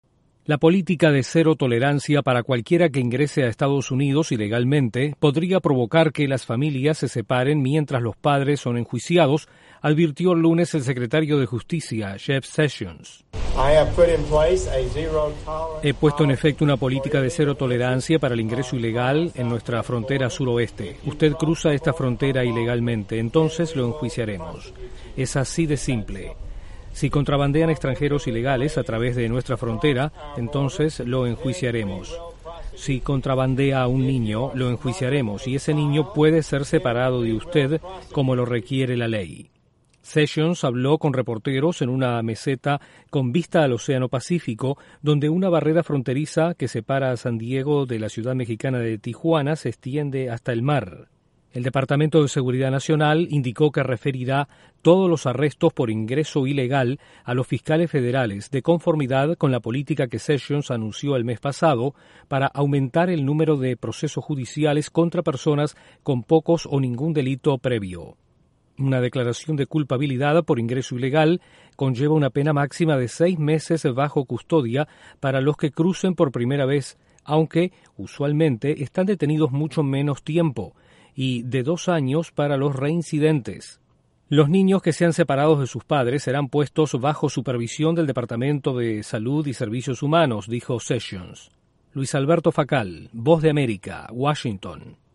El secretario de Justicia de EE.UU. advierte que podría separar a padres e hijos que inmigren ilegalmente. Desde la Voz de América en Washington informa